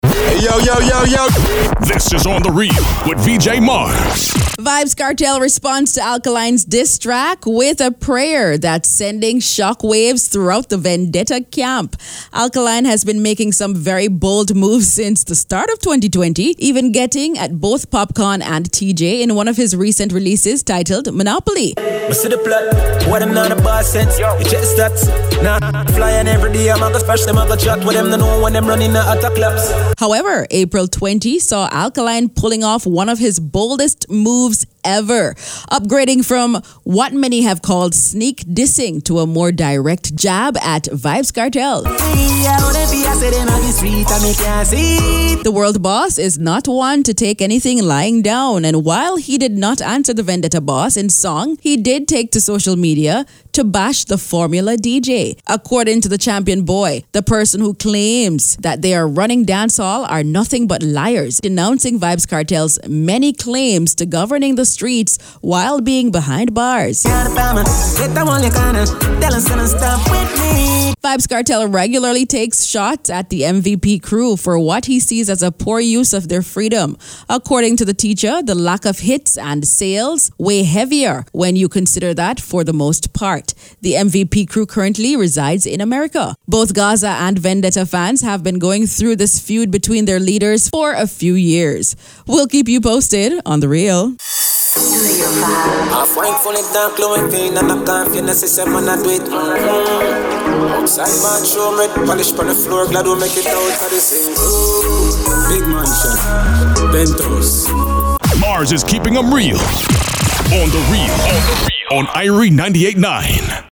interviews with the artists marking the news